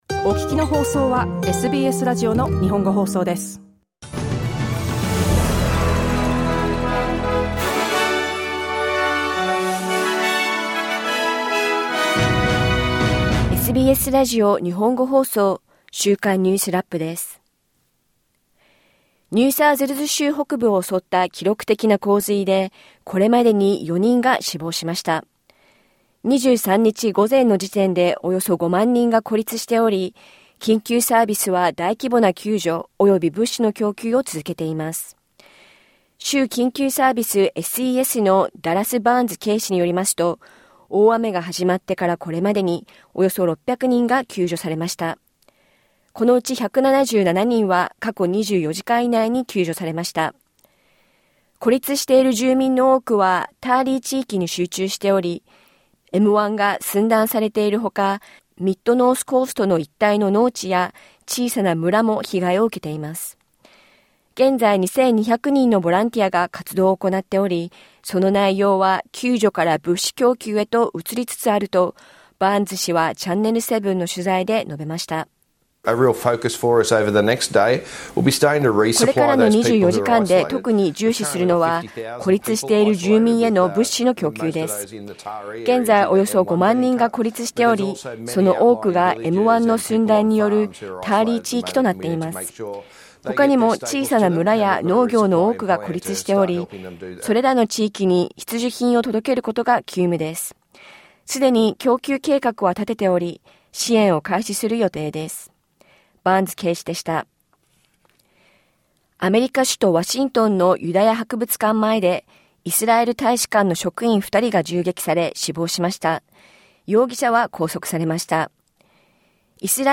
1週間を振り返るニュースラップです。